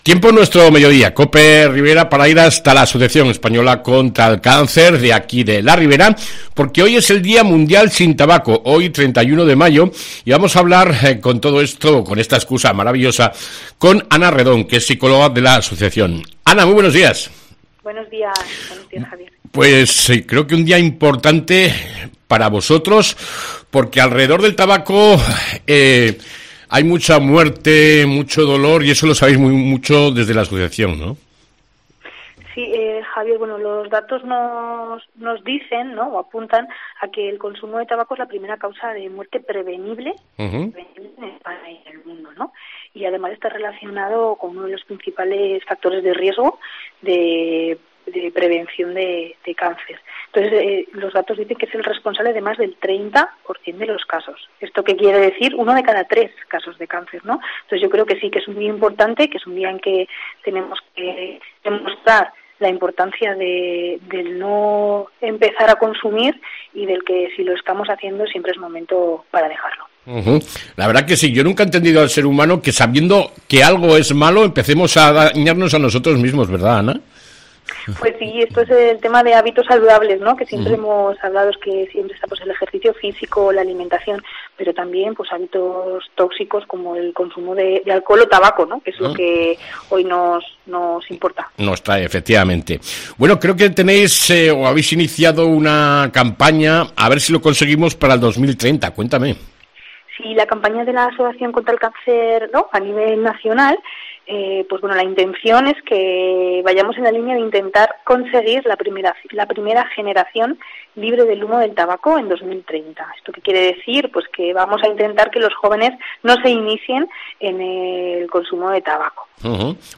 ENTREVISTA CON LA AECC